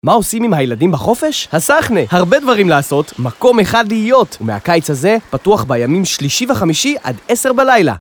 מגיש טלוויזיה, מגיש רדיו, איש תקשורת ועיתונאי, מקריין ומדבב, מנחה ומרצה באירועים